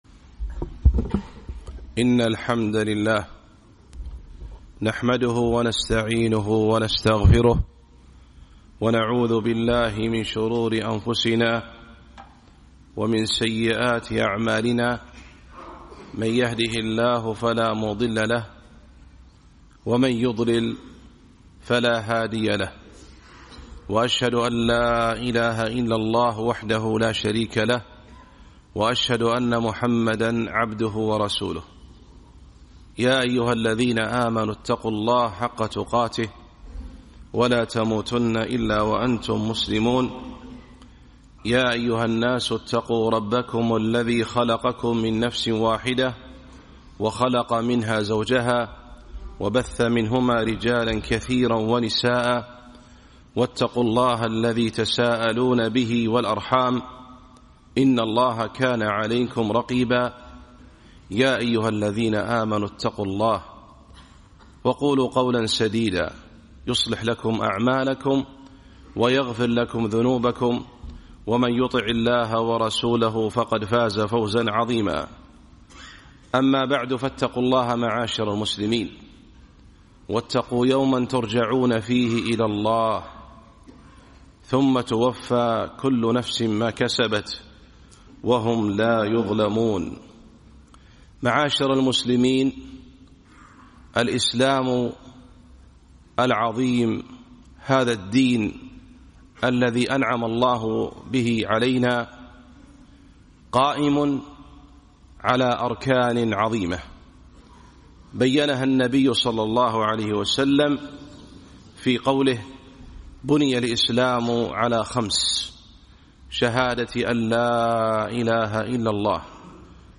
خطبة - توحيد الشهادتين